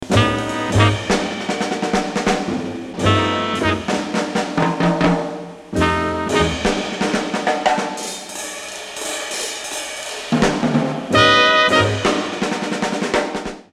A bluesy stop-time song